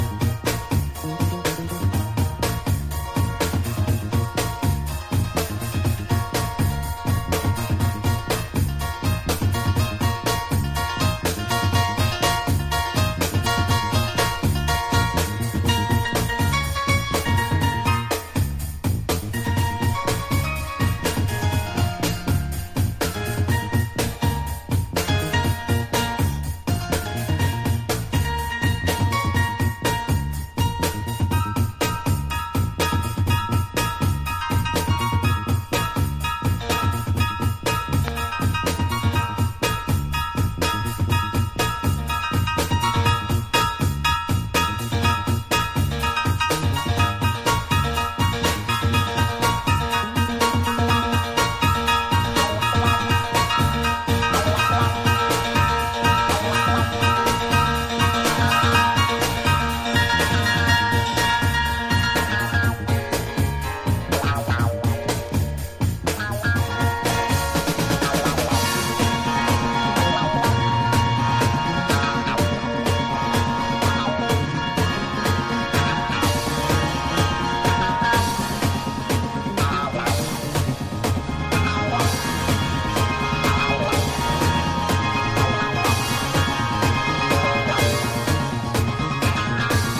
# NU-DISCO / RE-EDIT